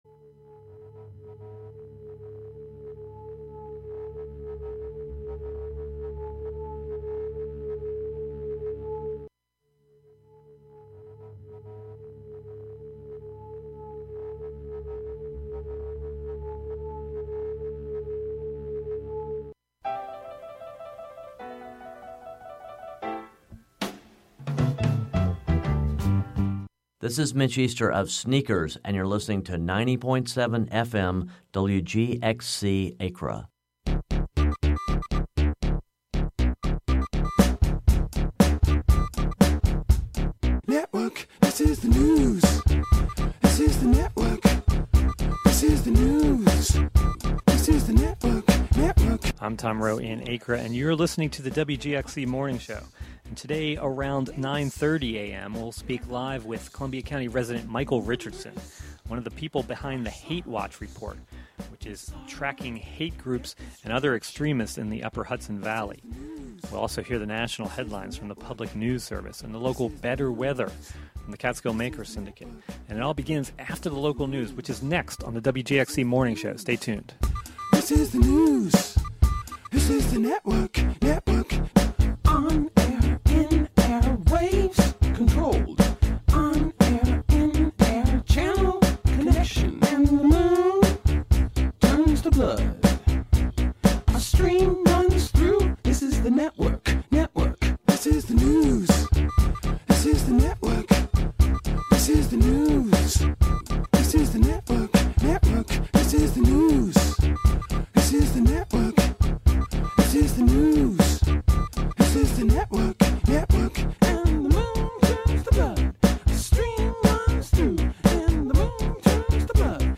Contributions from many WGXC programmers.
The site is maintained by social justice activists who monitor hate groups and other extremists in the Upper Hudson Valley and Taconic Hills and expose their activities to the public and the media. The "WGXC Morning Show" is a radio magazine show featuring local news, interviews with community leaders and personalities, reports on cultural issues, a rundown of public meetings and local and regional events, with weather updates, and more about and for the community, made mostly through volunteers in the community through WGXC.